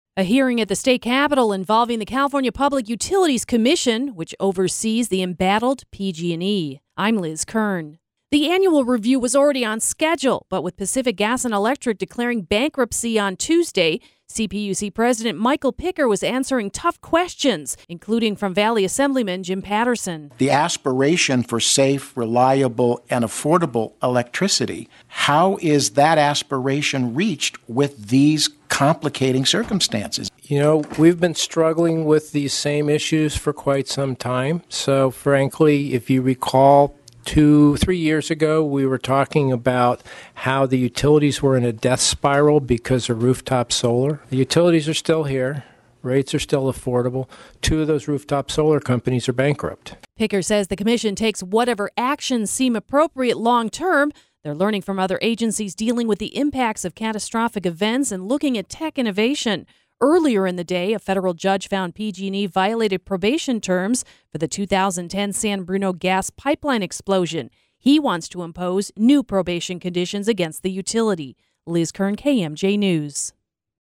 SACRAMENTO, CA (KMJ) – Tough questions were posed during a hearing at the State Capitol involved the California Public Utilities Commission, which oversees the embattled utility, PG&E.
The annual review was already on the schedule for Wednesday, but with Pacific Gas & Electric Co., the nation’s largest utility declaring bankruptcy at midnight on Tuesday, CPUC President Michael Picker was responding to legislators, including Valley Assemblyman Jim Patterson.